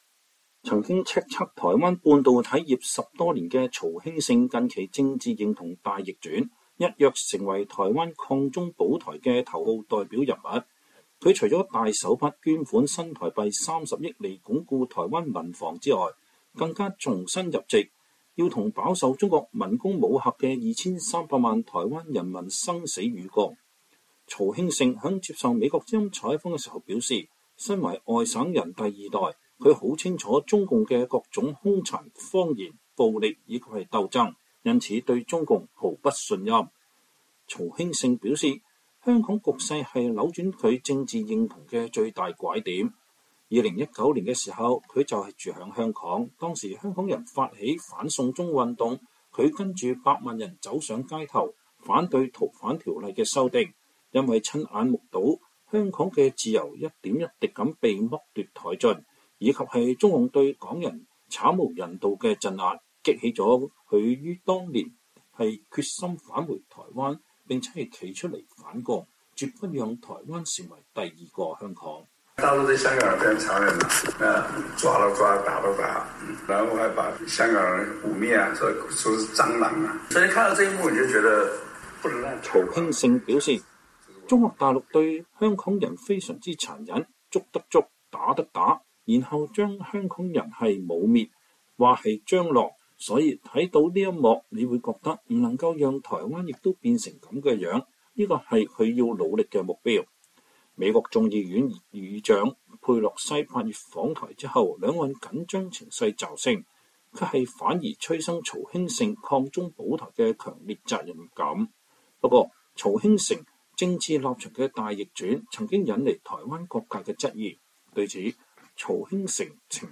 曹興誠在接受美國之音專訪時表示，台灣不可能靠兩岸對話就能避戰，唯有強化自我防衛，才能抵禦中共入侵。他還批評說，中共領導人習近平腦袋裡裝的都是毛澤東灌輸的舊思維，如果繼續連任，恐帶給中國重大災難。